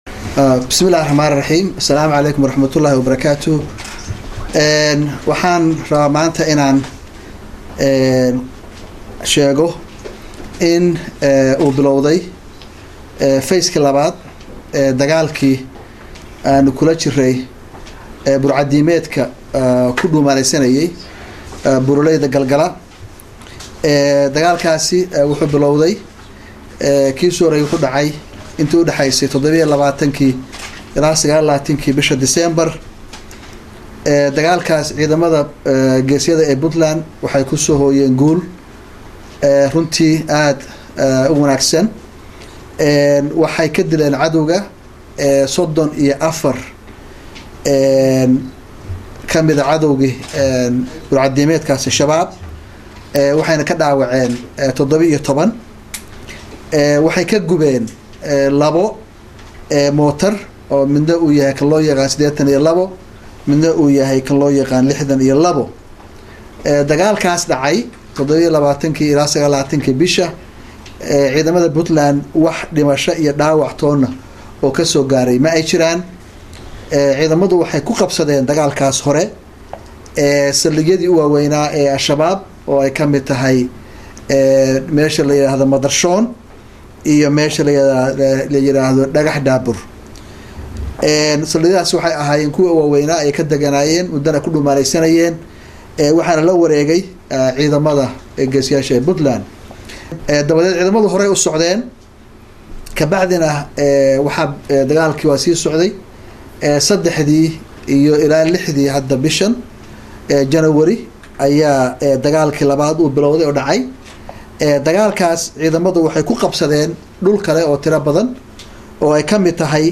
Talaado, Janaayo 06, 2015 (HOL) — Madaxweynaha Puntland, C/weli Max’ed Cali Gaas oo shir jaraa’id ku qabtay Garoowe gellinkii dambe ee maanta ka hadlay dagaallada Gal-gala ku dhexmaraya ciidamada Puntland iyo Al-shabaab, isagoo xusay in dagaalladaas ay ku dileen 20 ka tirsan xoogagga Al-shabaab ayna kaga qabteen saraakiil sar-sare.
DHAGEYSO: Shirka Jaraa'id ee Madaxweynaha Puntland